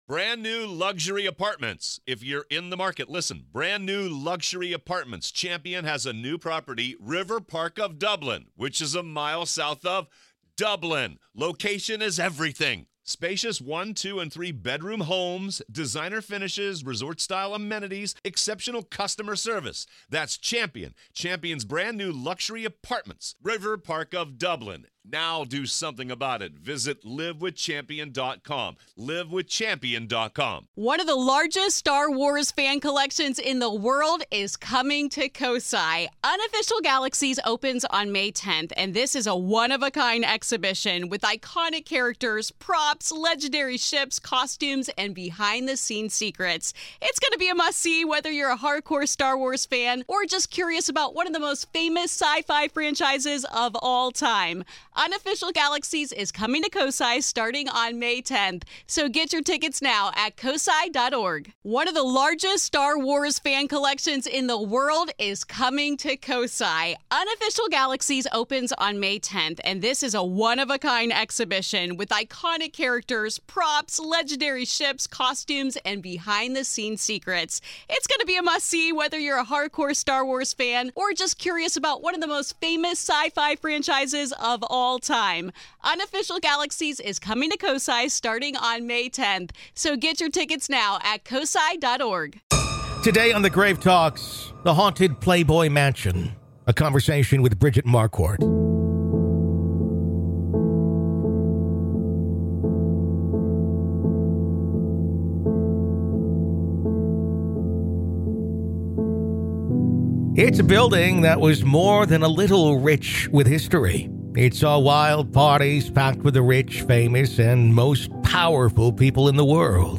Bridget Marquardt Interview 🪦 Grave Talks Classic